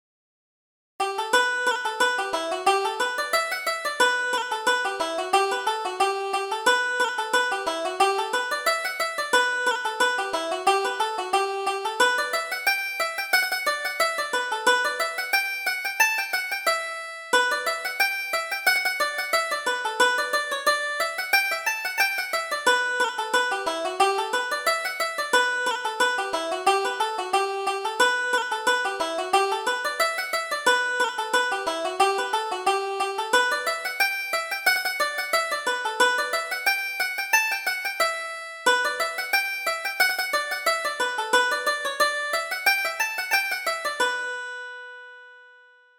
Reel: The Morning Star